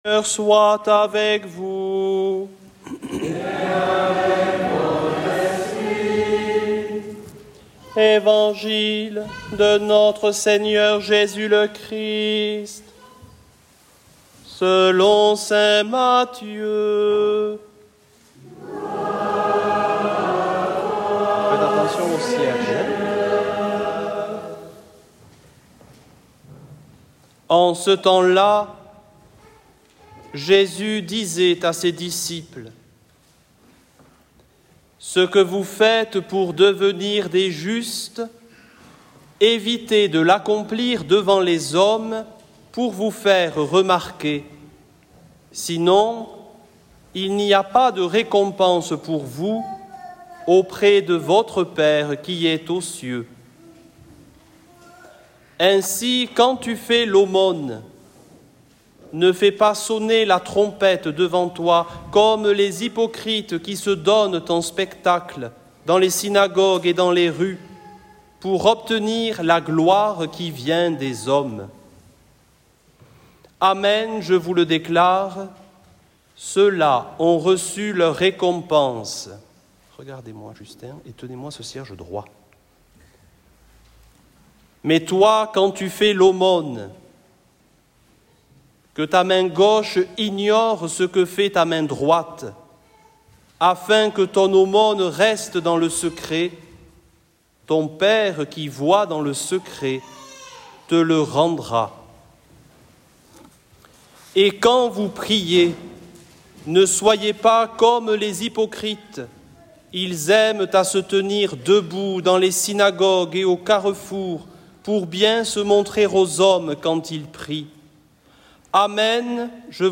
evangile-mdcendres-2025.mp3